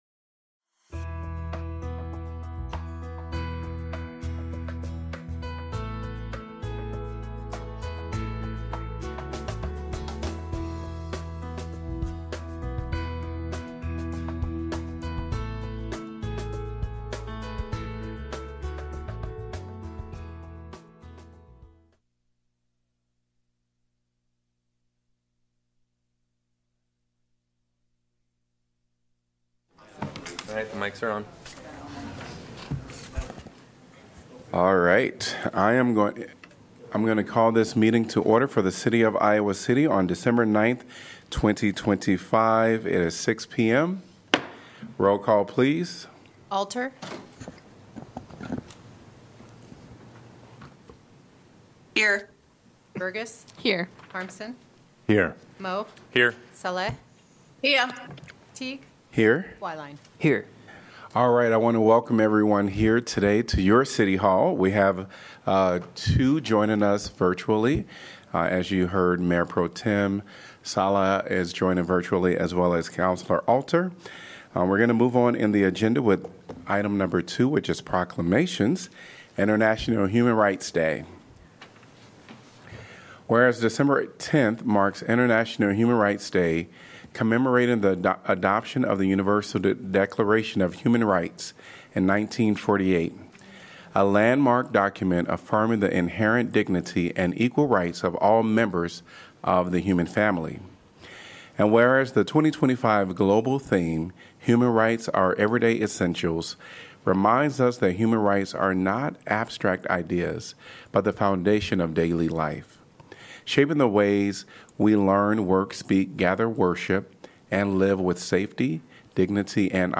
Audio podcast of the Iowa City Council meetings from the City's Cable TV Division.